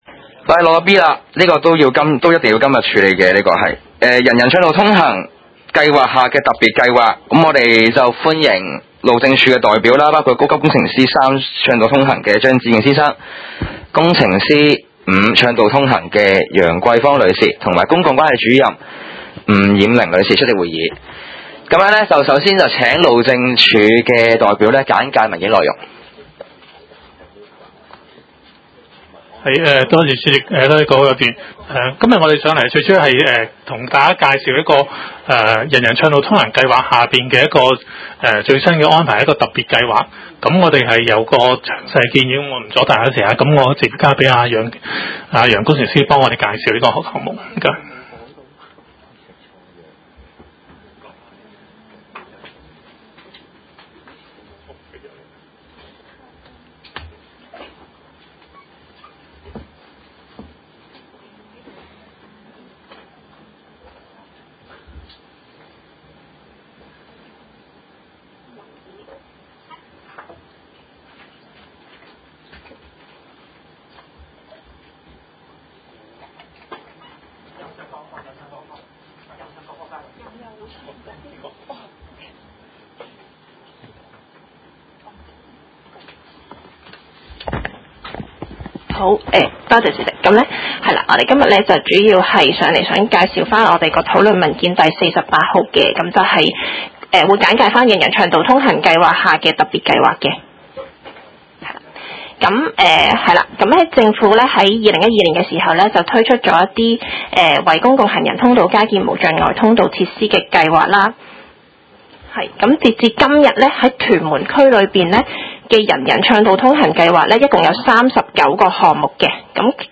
屯門區議會 - 委員會會議的錄音記錄
會議的錄音記錄